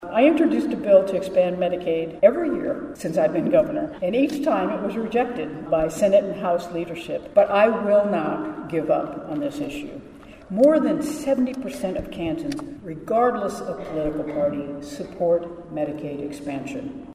KS Gov. Kelly speaks to hundreds of health conference attendees